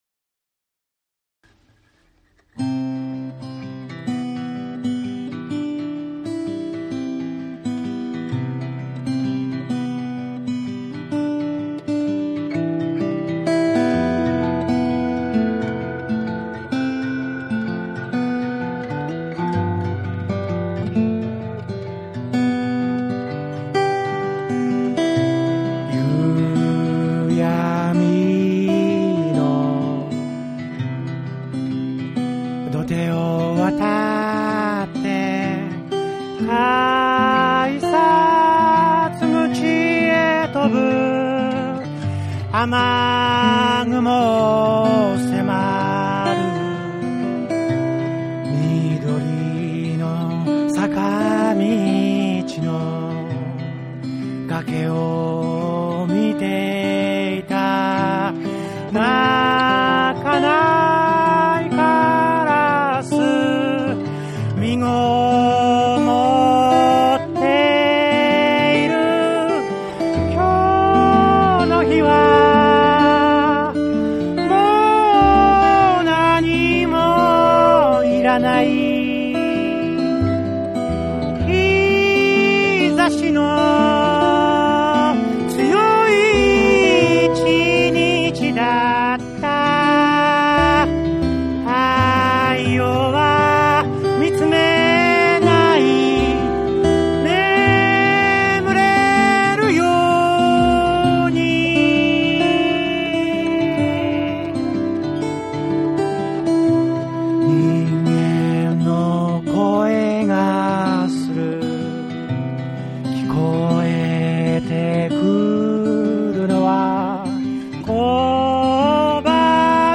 シンプルながらも繊細で力強い音楽が詰まった唯一無二の一枚が誕生した。
JAPANESE / NEW WAVE & ROCK / NEW RELEASE(新譜)